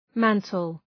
Shkrimi fonetik {‘mæntəl}